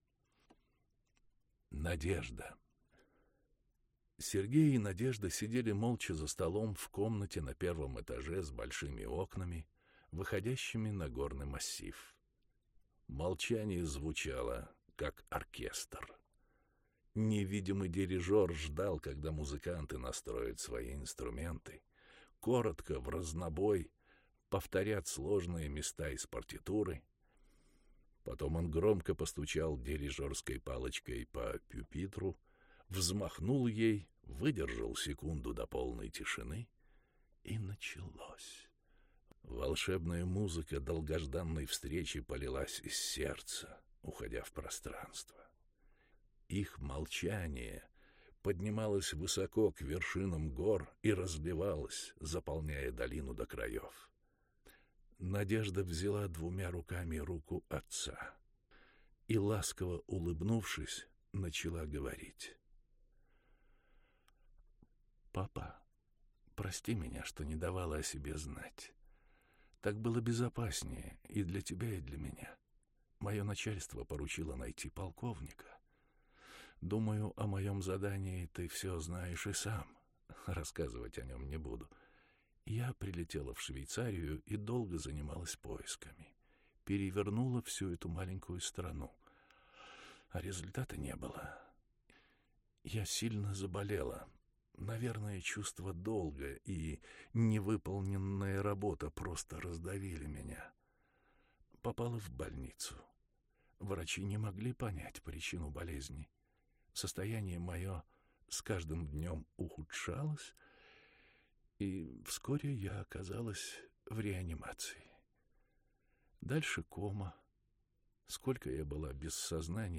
Аудиокнига Основано на реальных событиях. Выбор | Библиотека аудиокниг